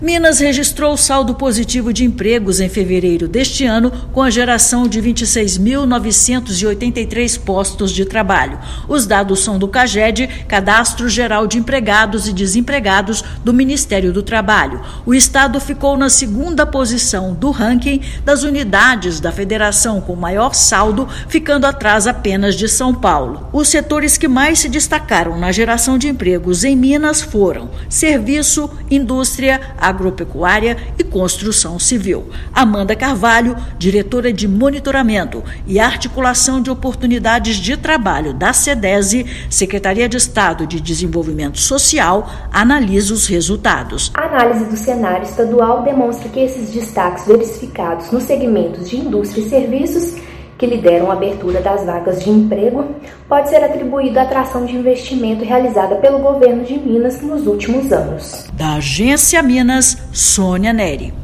Setor de serviços liderou a geração de vagas, seguido por indústria e agropecuária. Ouça matéria de rádio.